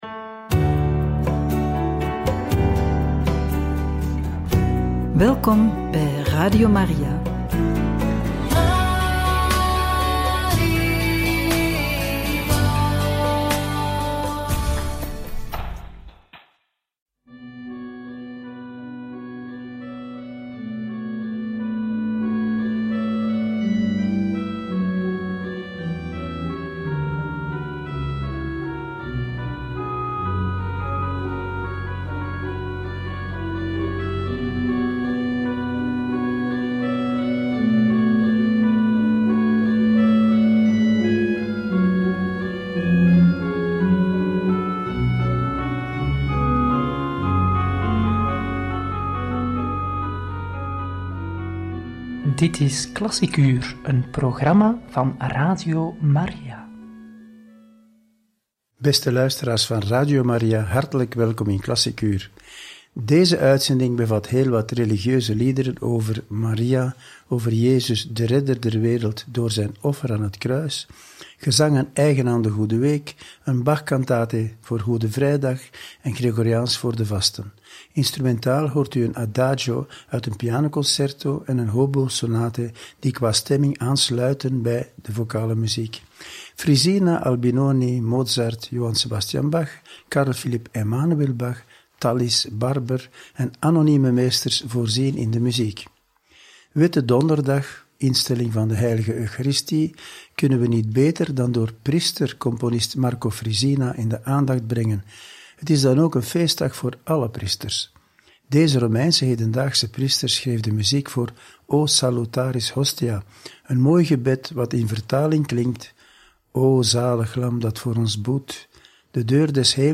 Religieuze liederen over Maria, Jezus, de Goede week en Goede Vrijdag – Radio Maria
religieuze-liederen-over-maria-jezus-de-goede-week-en-goede-vrijdag.mp3